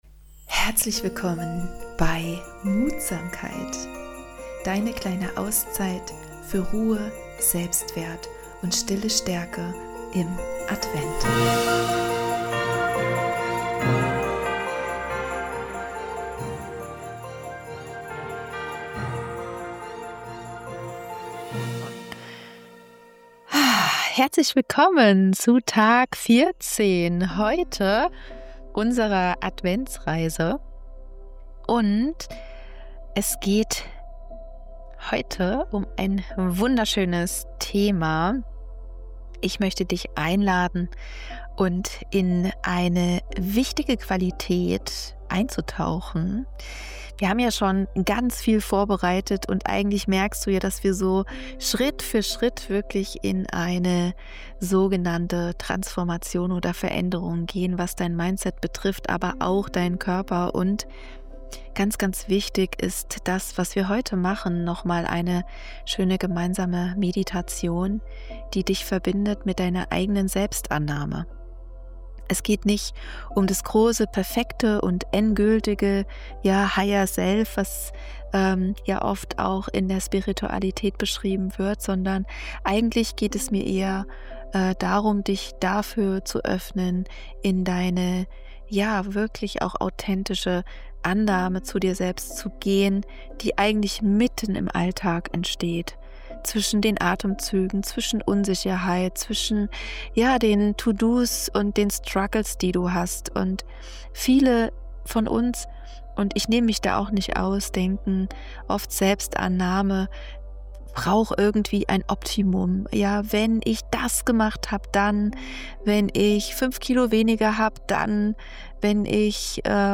Diese Meditation verbindet dich mit echter Selbstannahme.
Sie begleitet dich durch einen ruhigen, klaren Prozess der Selbstannahme – ohne Druck, ohne Idealbild, ohne Erwartungen.